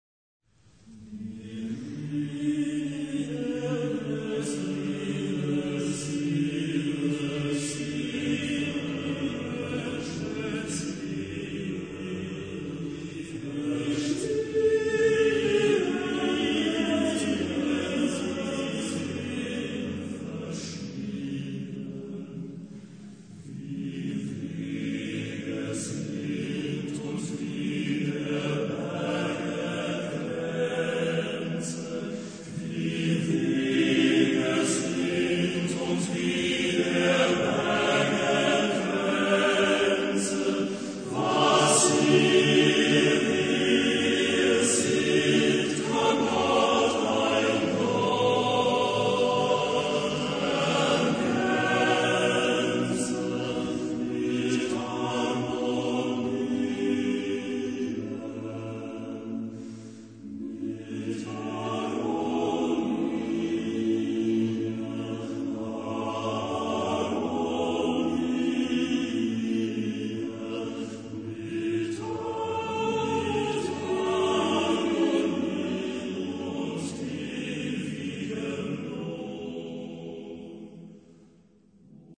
Genre-Stil-Form: weltlich ; Lied
Chorgattung: TTBB (div)  (4 Männerchor Stimmen )
Tonart(en): D-Dur